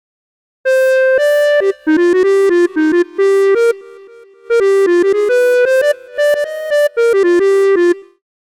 Rideのインストゥルメントモード
DynAssist_Inst_On.mp3